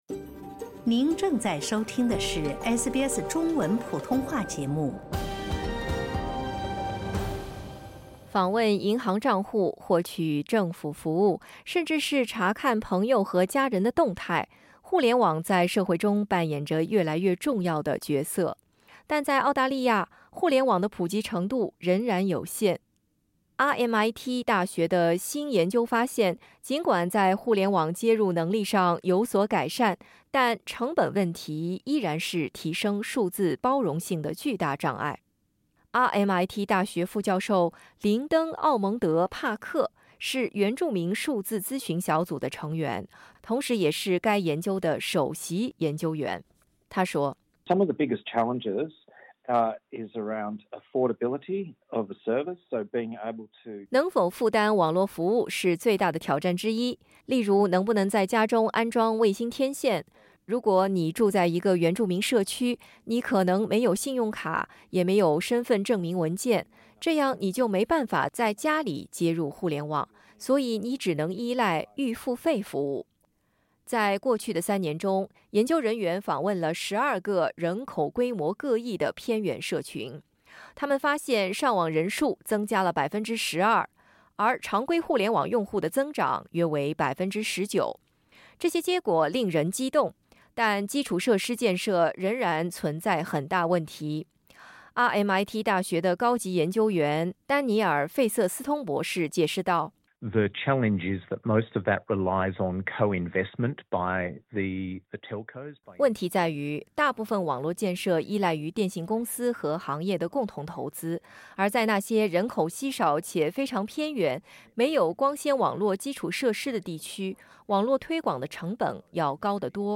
偏远地区原住民群体的互联网使用量正在不断增长，但成本仍是障碍。皇家墨尔本理工大学（RMIT University）的一项新研究表明，尽管有所进展，但要消除数字化鸿沟仍需付出更多努力。请点击音频，收听报道。